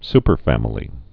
(spər-fămə-lē)